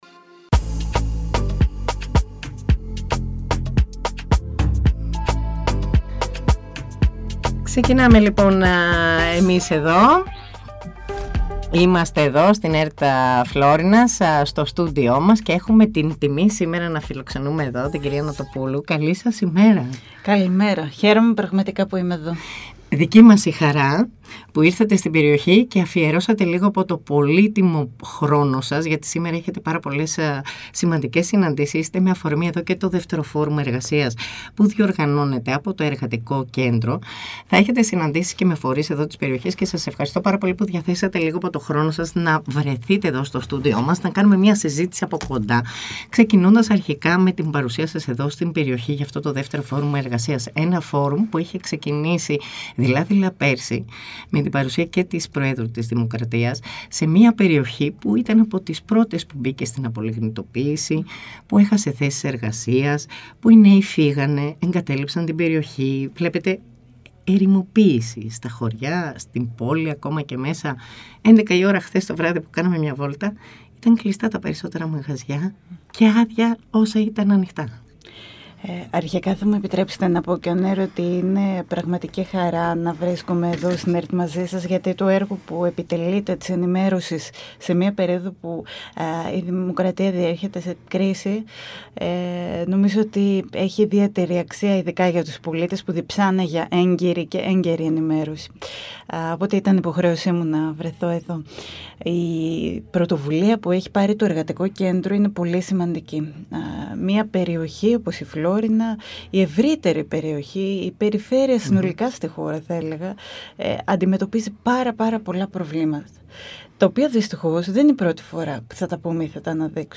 Στη σημερινή εκπομπή μιλάμε με την Κατερίνα Νοτοπούλου για το 2ο Forum Εργασίας στη Φλώρινα, την γενικότερη πολιτική της χώρας και τα προβλήματα της περιοχής .
Πρωϊνό ενημερωτικό “κους-κους” με διαφορετική ματιά στην ενημέρωση της περιοχής της Φλώρινας και της Δυτικής Μακεδονίας, πάντα με την επικαιρότητα στο πιάτο σας.